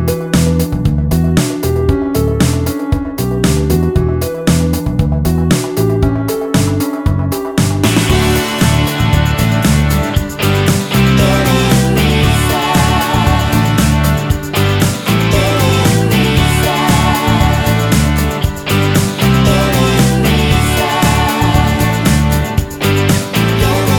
No Backing Vocals Irish 3:33 Buy £1.50